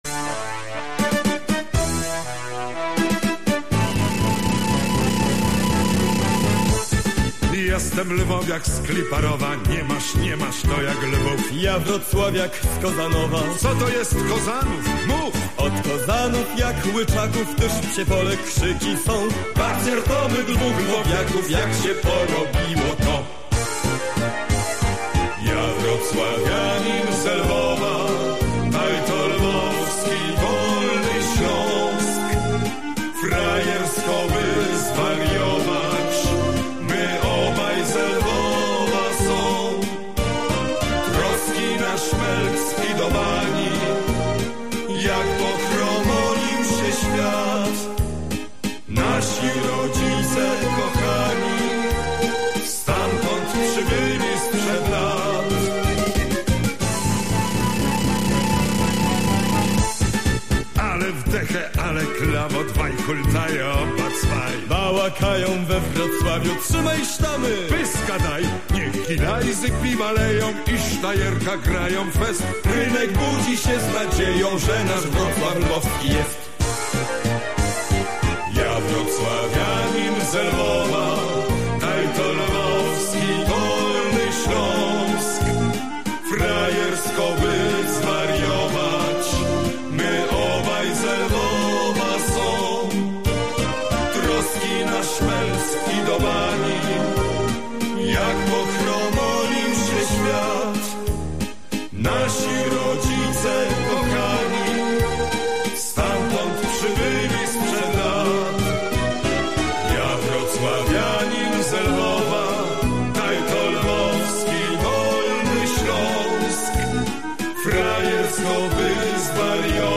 - Disco polo